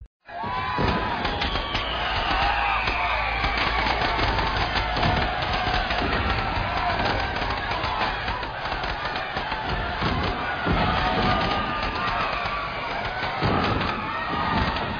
Night Diwali Crackers Sound Effect Free Download
Night Diwali Crackers